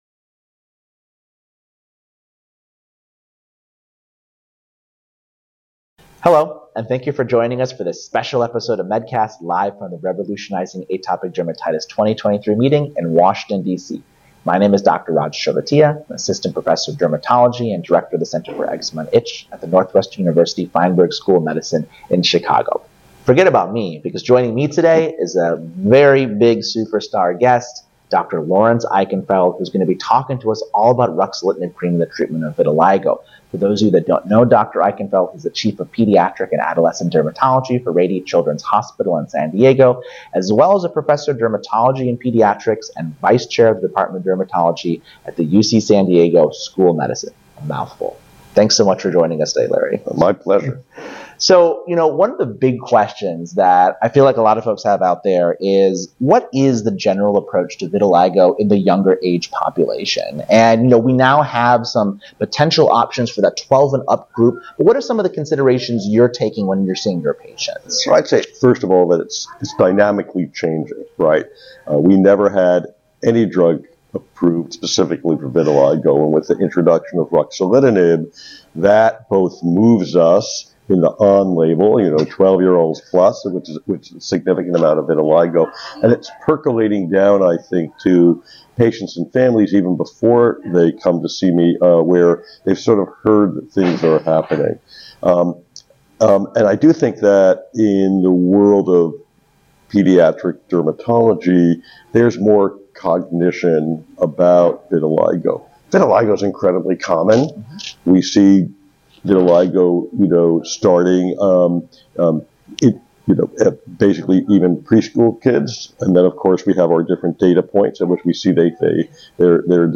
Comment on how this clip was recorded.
review the major shifts in pediatric vitiligo care and pharmacotherapy developments live from the Revolutionizing Atopic Dermatitis (RAD) 2023 Annual Meeting in Washington, DC.